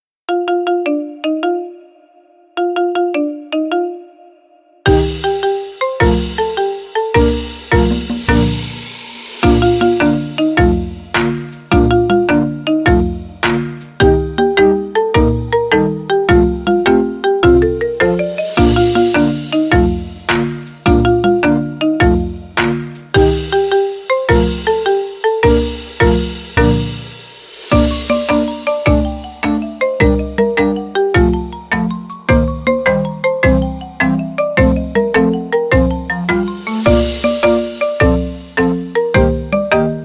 Kategorien Marimba Remix